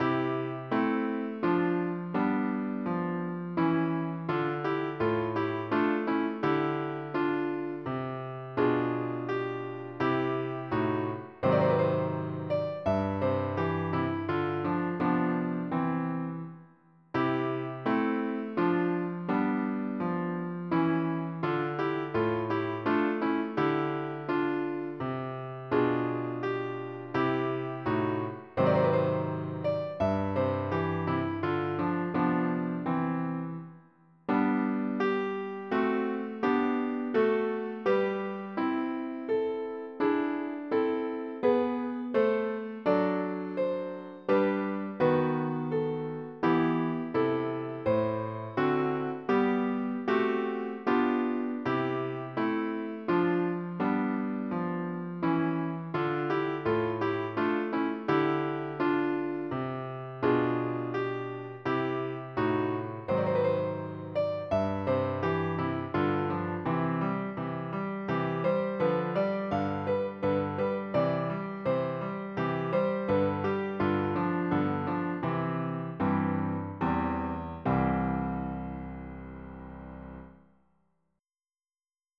piano
Type General MIDI